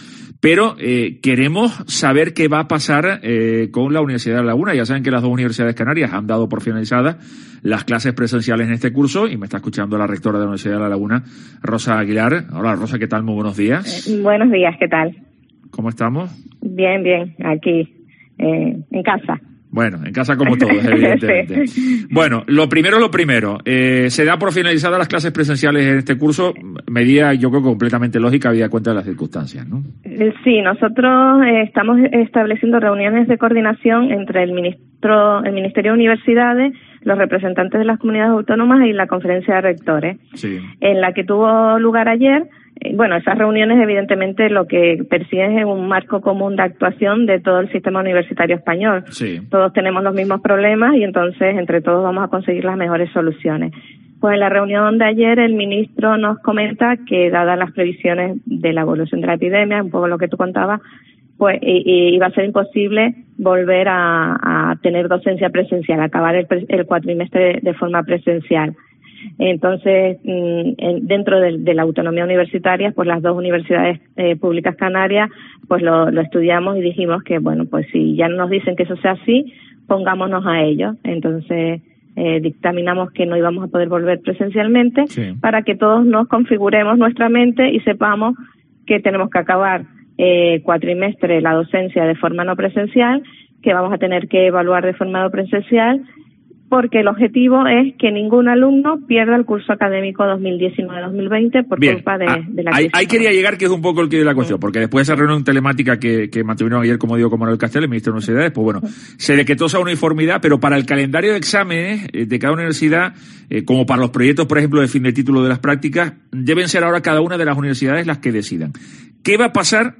La rectora de la Universidad de La Laguna, Rosa Aguilar, ha aclarado en una entrevista en COPE Tenerife una de las preguntas que más preocupan a los casi 20.000 estudiantes de la ULL y es qué ocurrirá con los exámenes, asegura Aguilar que “ en las convocatorias de exámenes de junio y julio no se acudirá al aula, no vamos a poder meter a 100 alumnos en un aula , eso sí, todavía hay que idear el mecanismo para evaluar a esos alumnos, y será distinto en Derecho, Periodismo o Biología, pero esperamos dar una instrucción general el viernes”.